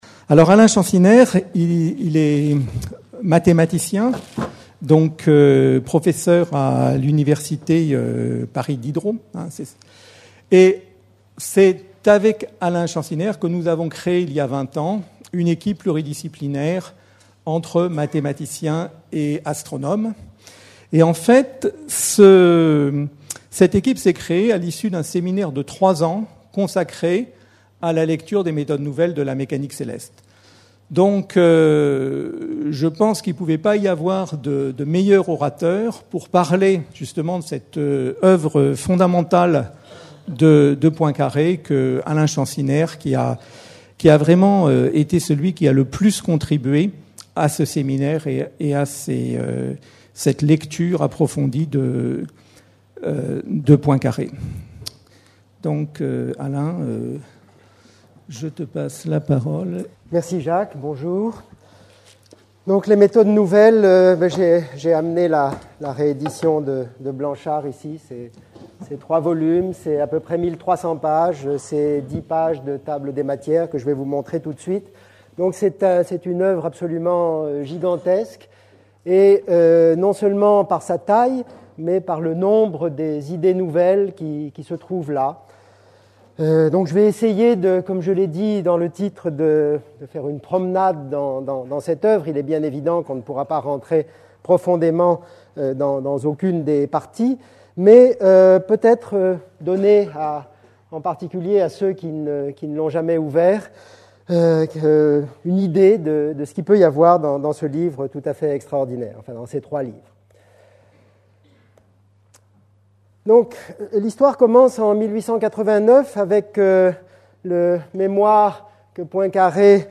Journée de commémoration du centenaire de la disparition de Henri Poincaré (29 avril 1854 - 17 juillet 1912), organisée à l'Institut d'Astrophysique de Paris le 9 Juillet 2012.